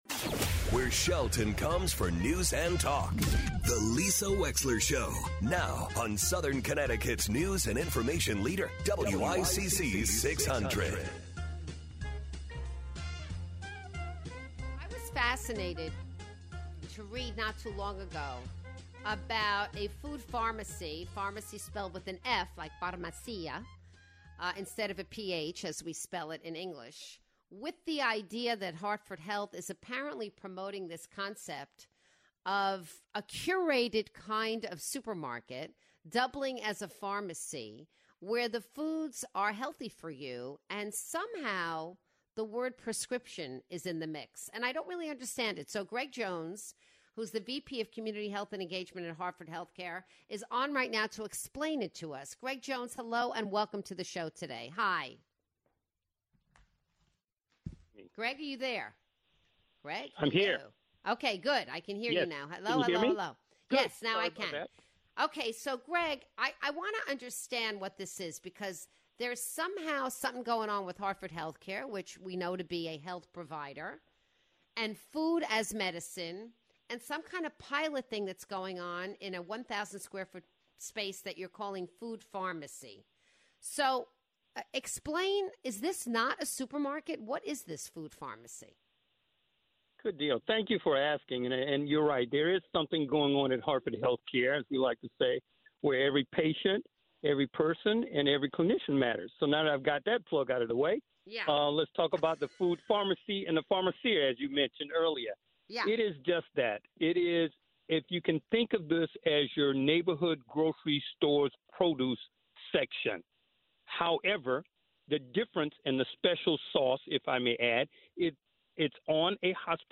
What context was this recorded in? calls in to talk about diet and getting fresh produce.